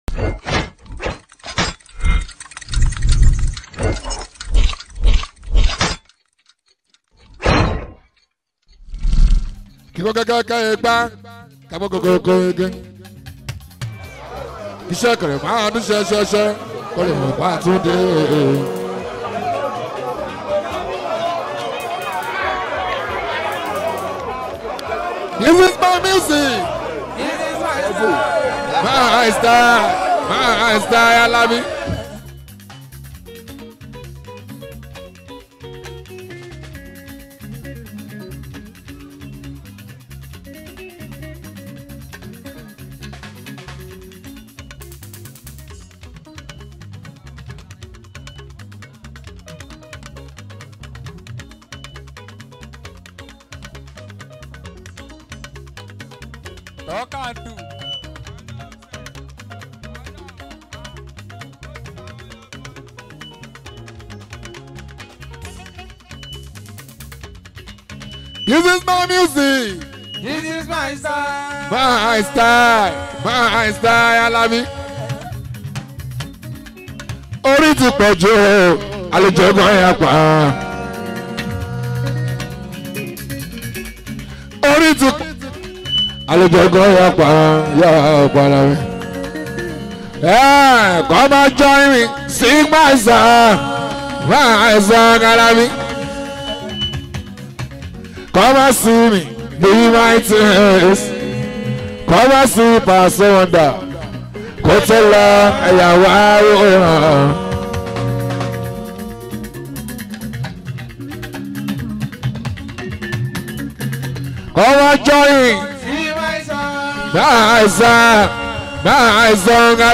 Fuji Music